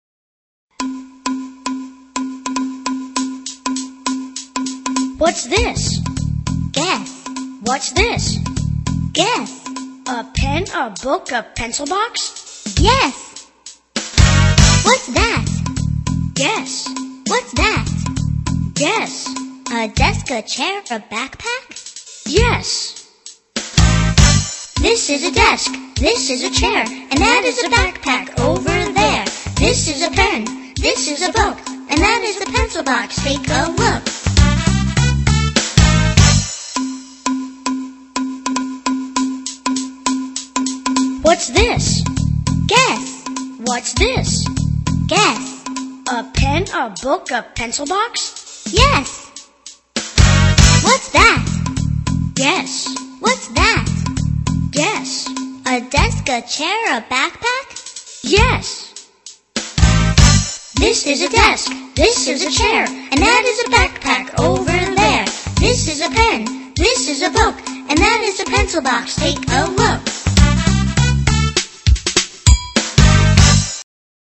在线英语听力室英语儿歌274首 第254期:What's this(3)的听力文件下载,收录了274首发音地道纯正，音乐节奏活泼动人的英文儿歌，从小培养对英语的爱好，为以后萌娃学习更多的英语知识，打下坚实的基础。